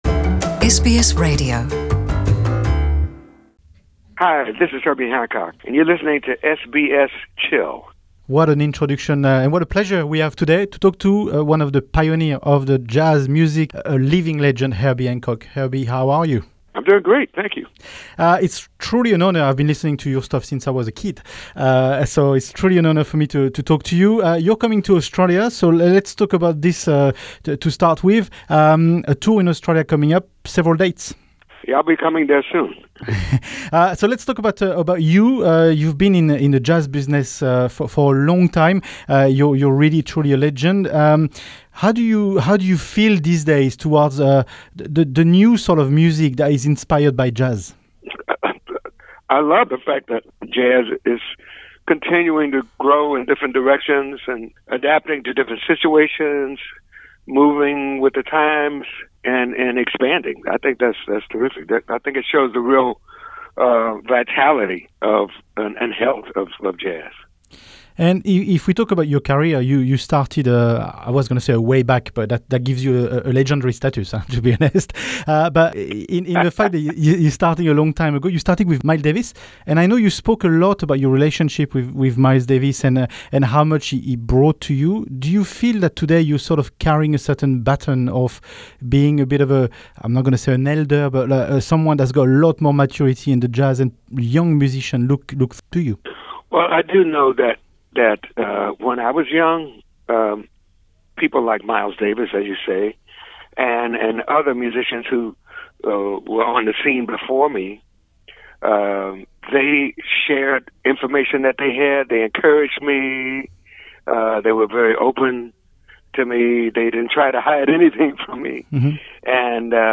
Herbie Hancock: Interview with a Living Legend of Jazz